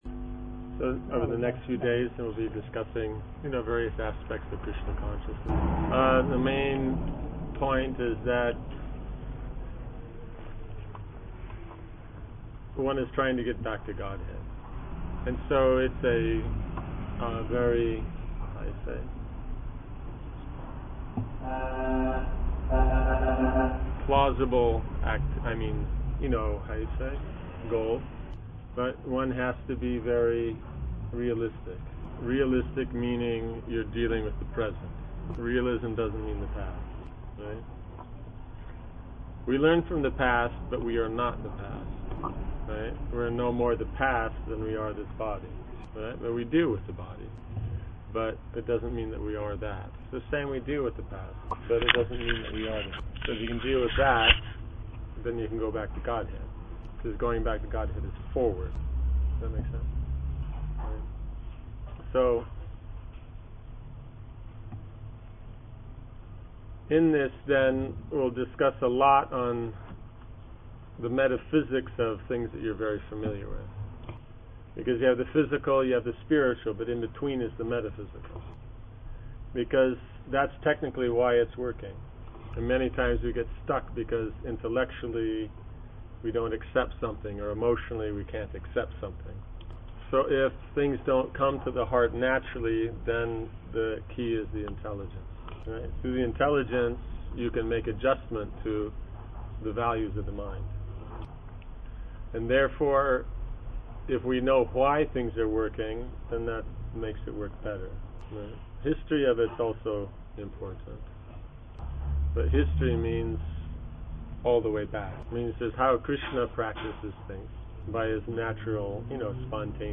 Seminar on Masculine and Feminine Interaction given in Eger, Hungary, July-August 2007